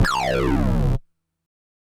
synth FX.wav